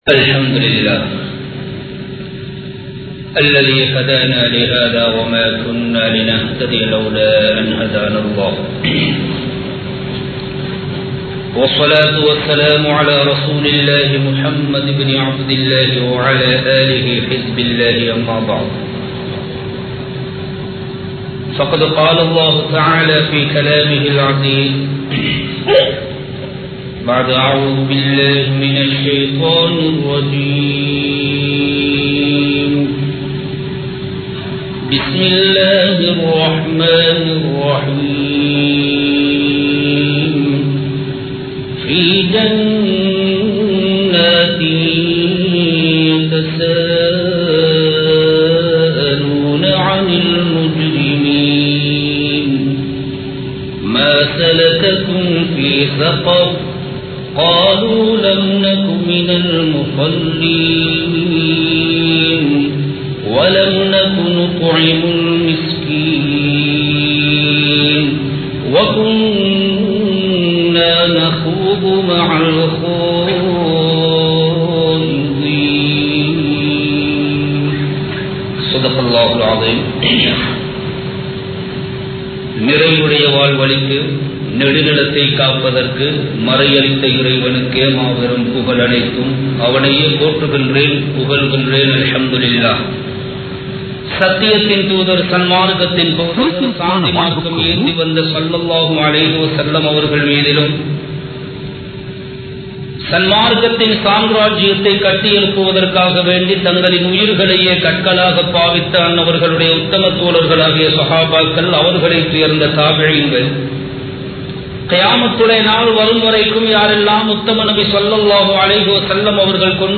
Tholuhaiyai Vittu Vidatheerhal (தொழுகையை விடாதீர்கள்) | Audio Bayans | All Ceylon Muslim Youth Community | Addalaichenai
Kollupitty Jumua Masjith